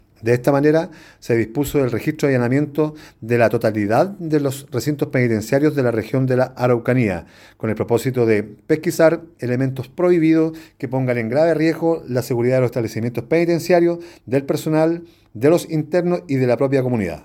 Así lo explicó el coronel Alberto Figueroa Quezada, director regional de la institución a cargo de las unidades penales en la región de La Araucanía.